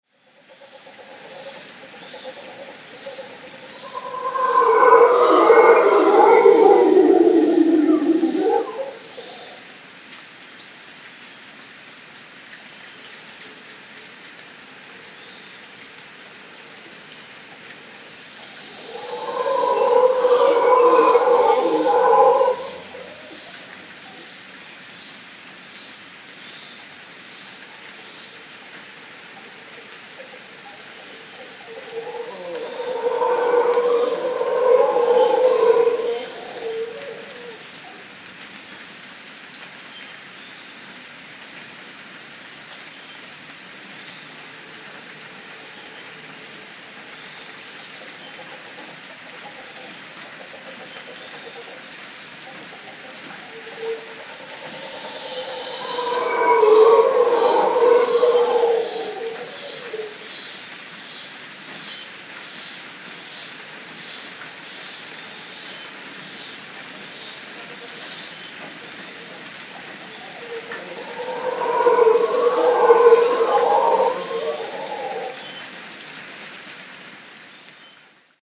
Howler Monkeys
Friendly howler monkeys in the morning
Holwer monkeys in the early morning close to Uvita, Costa Rica.
Recorded with ordinary IPhone7 in April 2013.
HowlerMonkeys_Costa_Rica.mp3